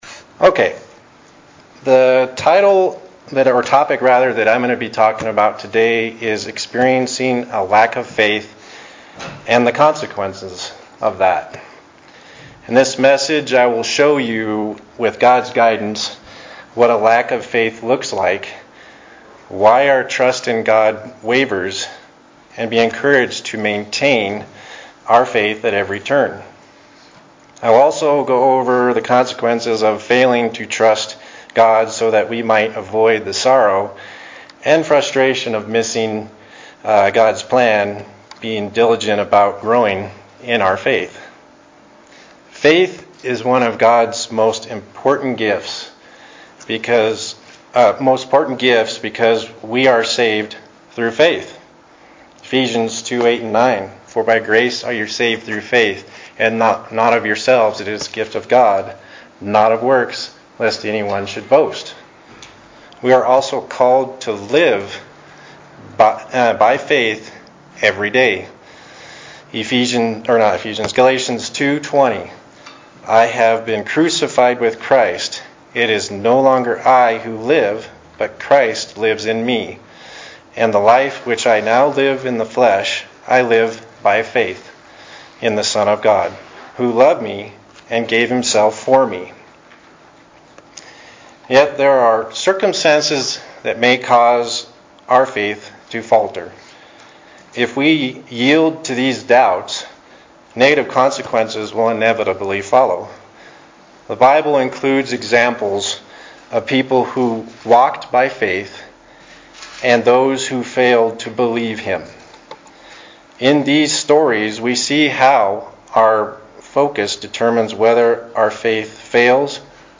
Forgot to start the recording before the opening prayer.